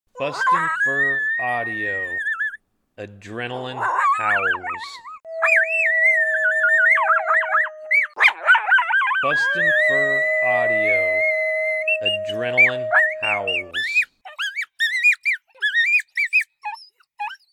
BFA Adrenaline Howls
BFA's juvenile male MotoMoto and juvenile male Cash, pair howling excitedly. Lots of excitement in this howl to fire up the Coyotes you're calling to!
BFA Adrenaline Howls Sample.mp3